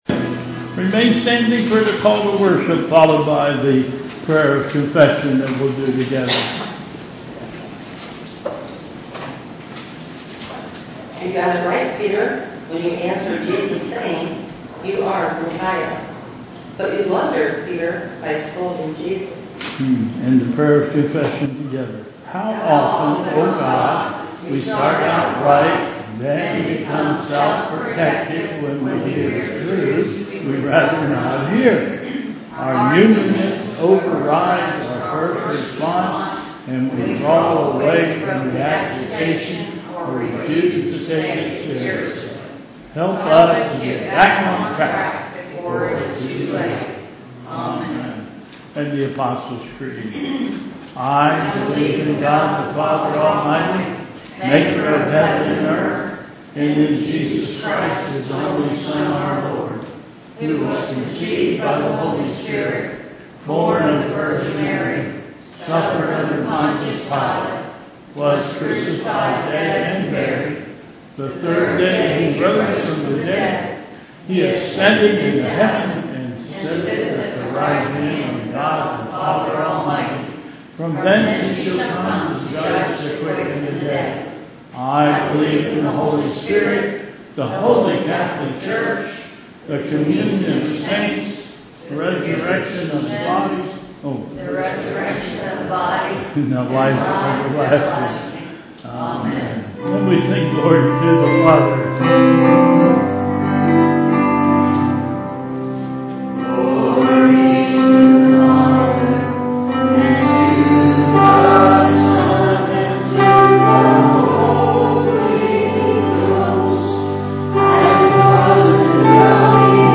Bethel Church Service
Call to Worship